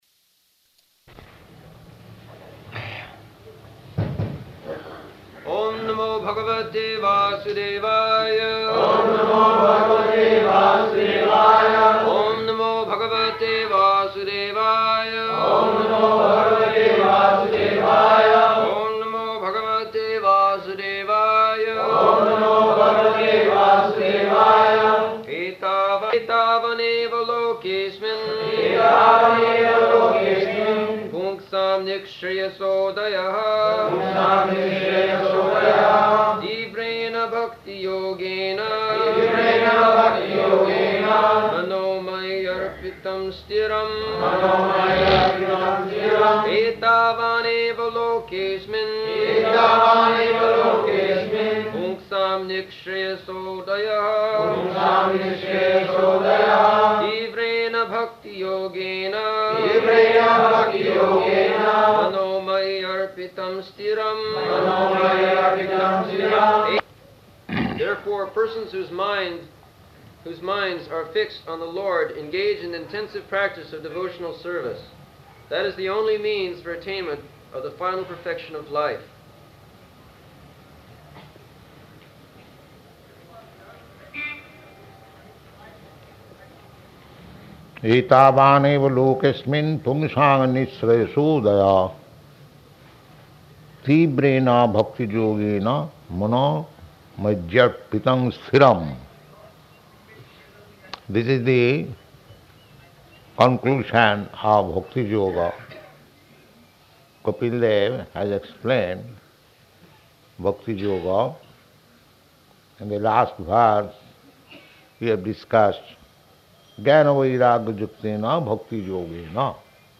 December 12th 1974 Location: Bombay Audio file
[devotees repeat] [leads chanting of verse, etc.]